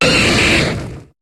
Cri de Magmar dans Pokémon HOME.